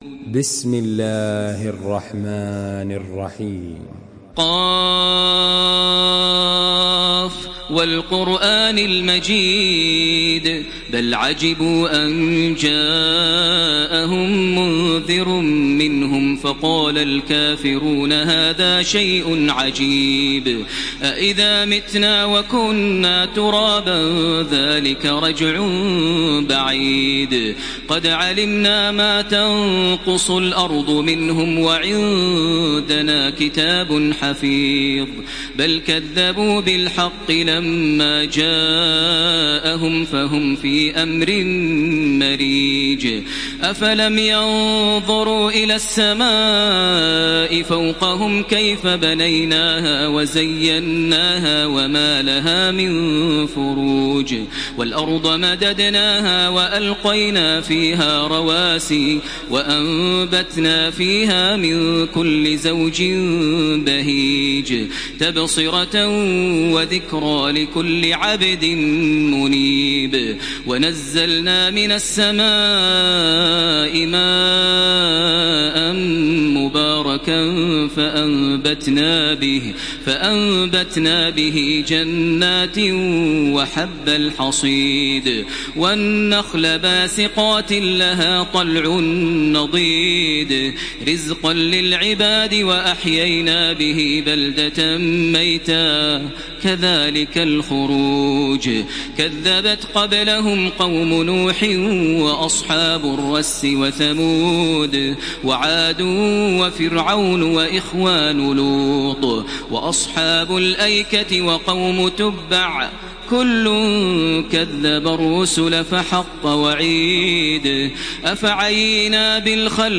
تحميل سورة ق بصوت تراويح الحرم المكي 1433
مرتل